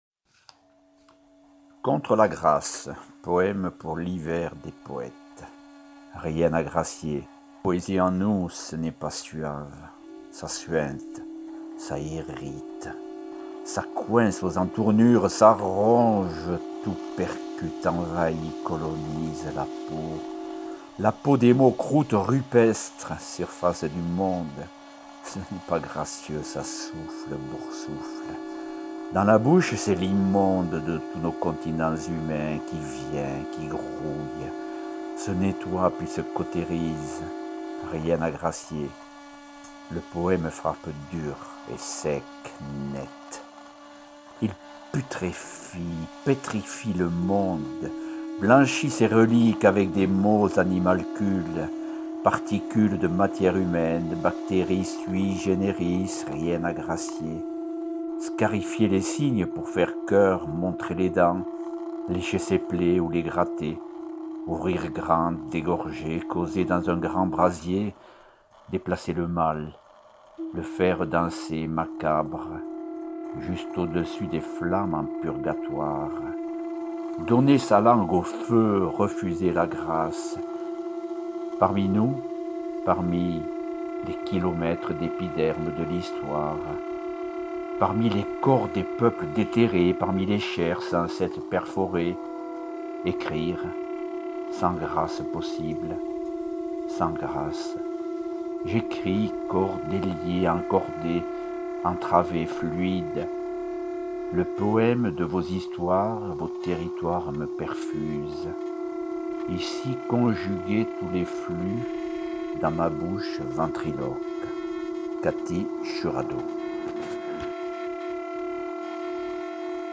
Musique Rodolphe Loubatière:     Extrait d'  "Acte II"  -  NEF Intervalles...